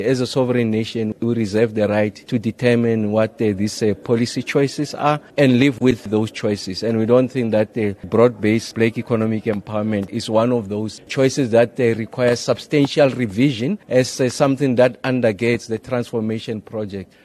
Nuus